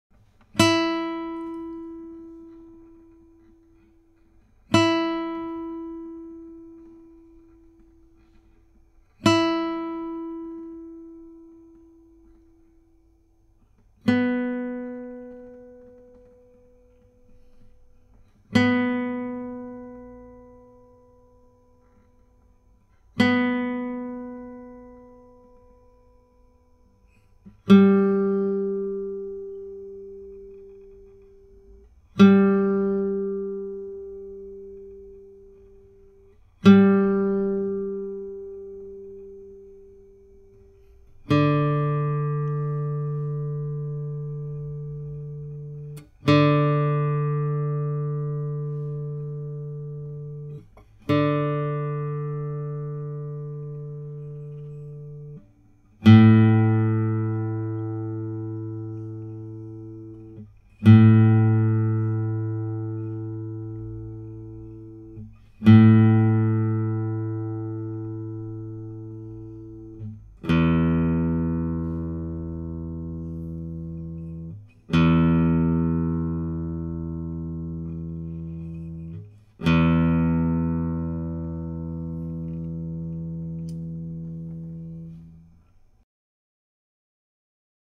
Meespeel CD
1. Stemtonen
01-Stemtonen.mp3